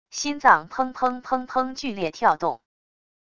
心脏砰砰砰砰剧烈跳动wav音频